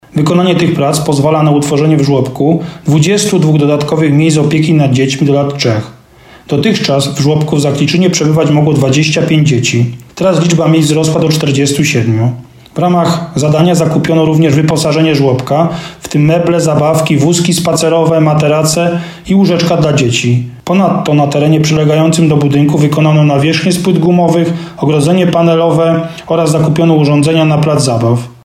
mówi burmistrz Zakliczyna Dawid Chrobak.